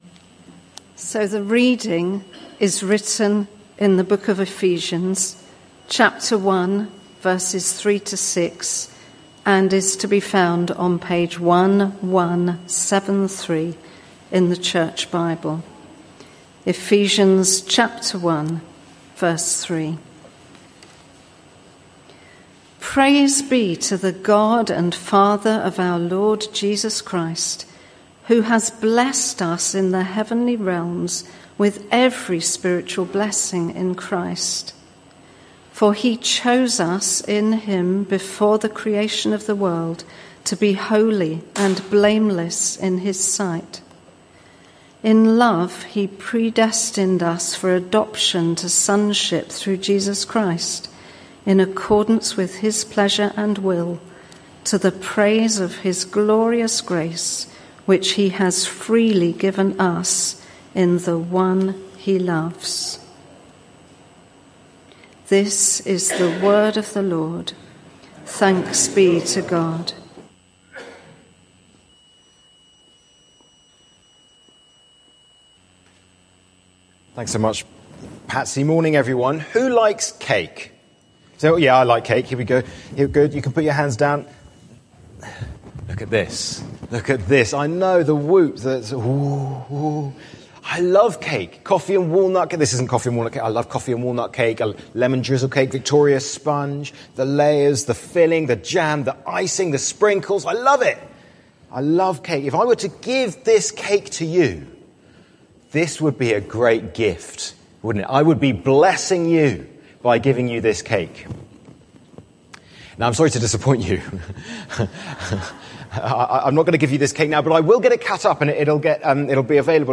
This sermon is part of a series: 10 August 2025